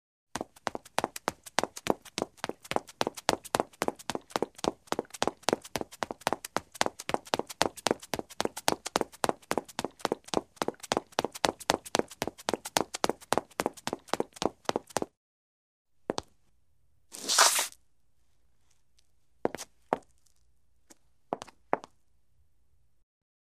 Звуки бегущего человека
Бег по асфальту в каблуках